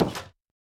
Minecraft Version Minecraft Version snapshot Latest Release | Latest Snapshot snapshot / assets / minecraft / sounds / block / nether_wood_door / toggle4.ogg Compare With Compare With Latest Release | Latest Snapshot